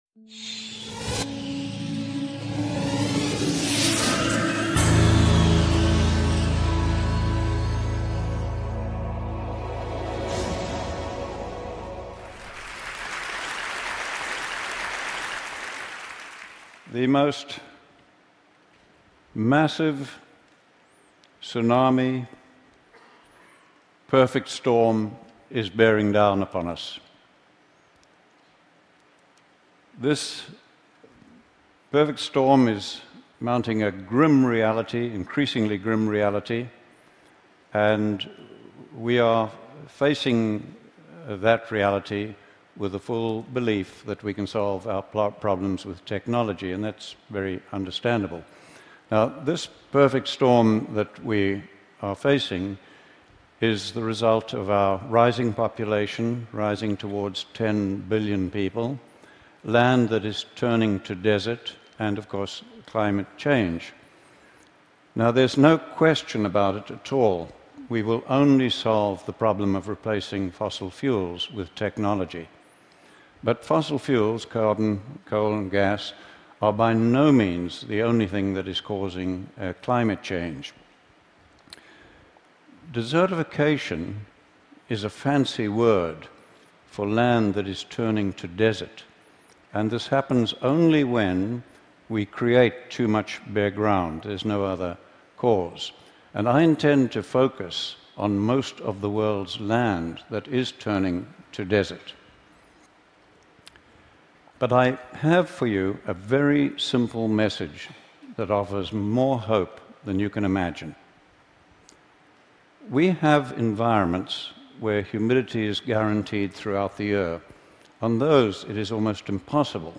NOTE: Statements in this talk have been challenged by other scientists working in this field.